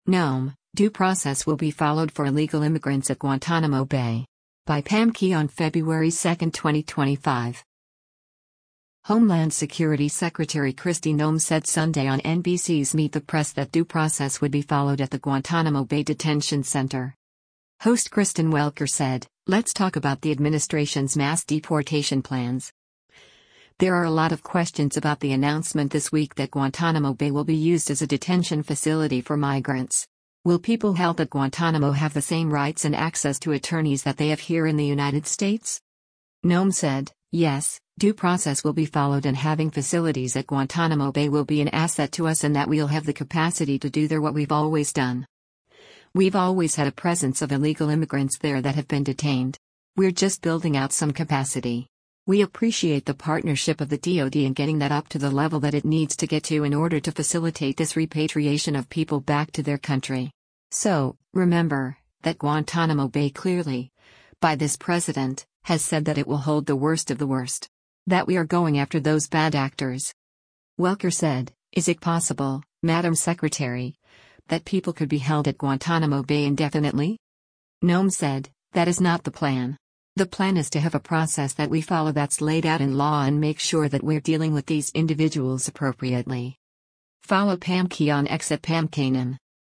Homeland Security Secretary Kristi Noem said Sunday on NBC’s “Meet the Press” that “due process” would be followed at the Guantanamo Bay detention center.